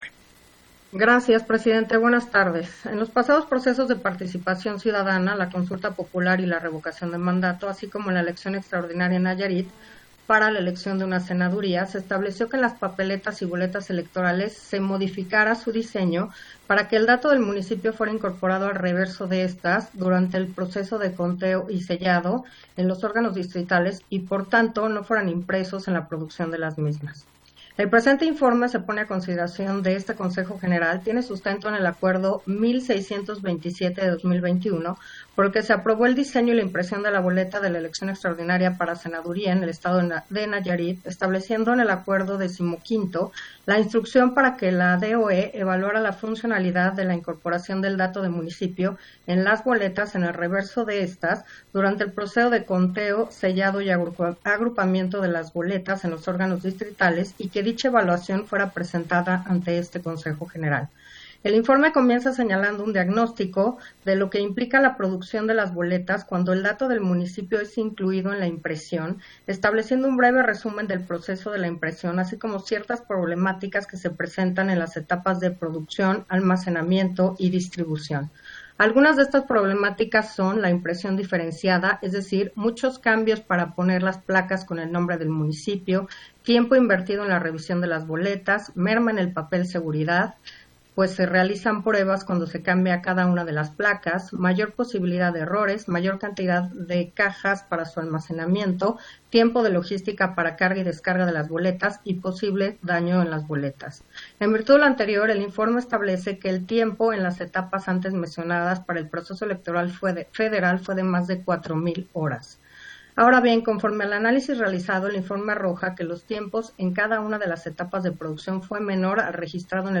200722_AUDIO_INTERVENCIÓN-CONSEJERA-HUMPHREY-PUNTO-2-SESIÓN-EXT. - Central Electoral